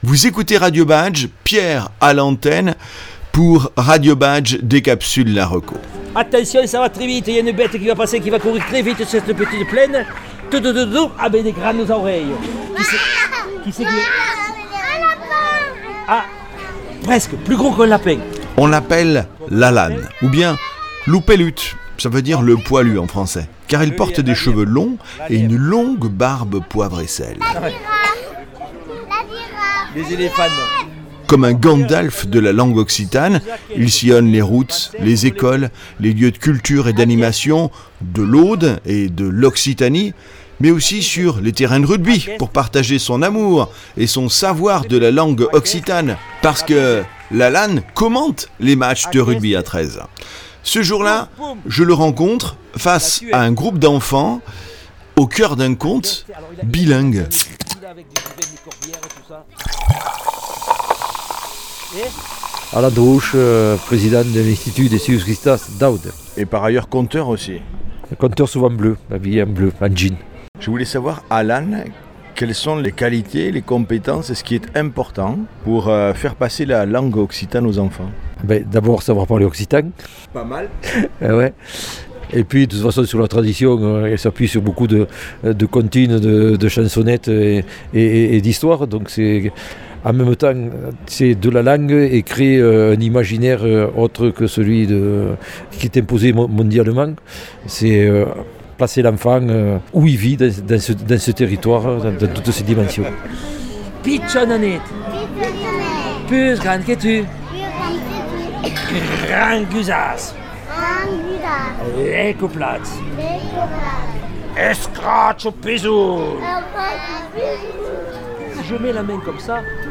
Le jour de notre rencontre il accueille nombre classes de primaire du département de l'Aude et transmet aux enfants des expressions et du vocabulaire Occitan.